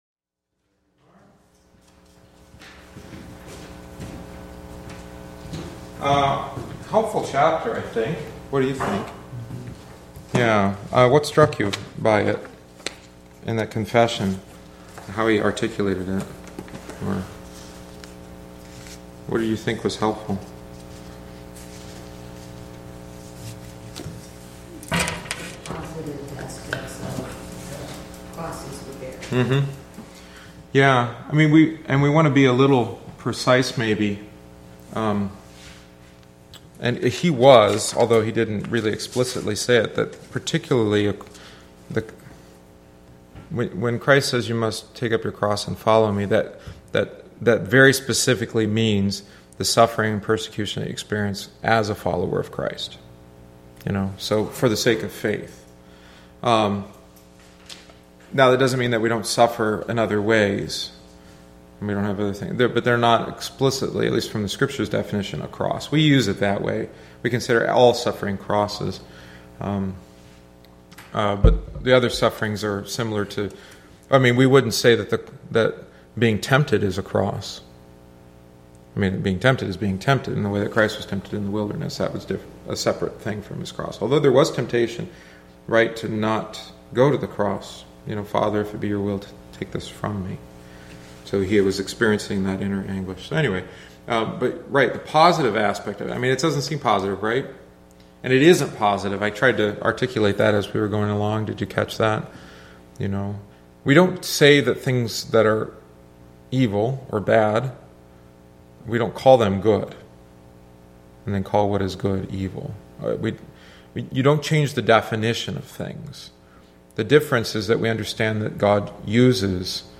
The attached audio omits the text of the book and includes only our discussion.